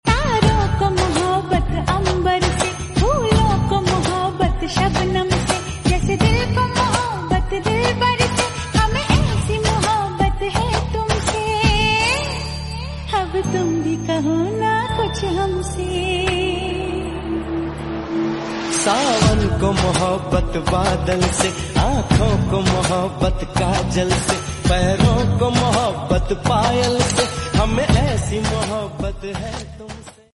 Full Song Slow Reverb